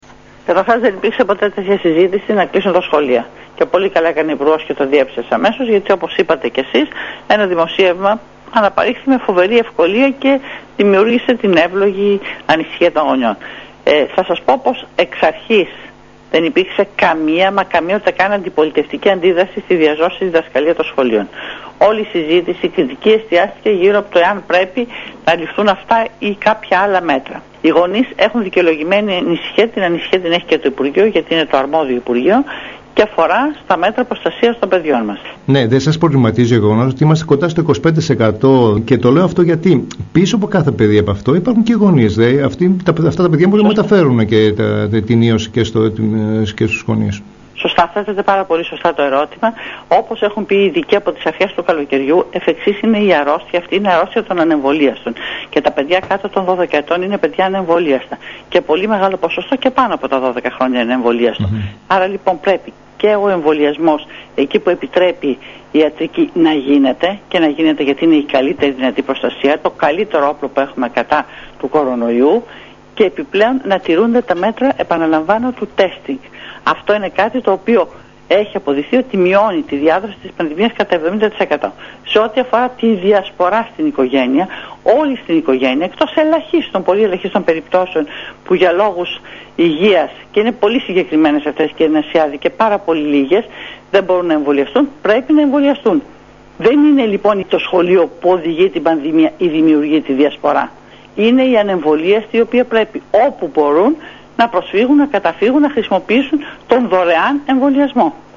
Αυτό τόνισε μιλώντας στην ΕΡΤ Κοζάνης υφυπουργός Παιδείας Ζέτα Μακρή, η οποία με τη σειρά της διέψευσε τα δημοσιεύματα περί κλεισίματος σχολείων, λόγω των αυξημένων κρουσμάτων.